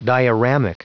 Prononciation du mot dioramic en anglais (fichier audio)
Prononciation du mot : dioramic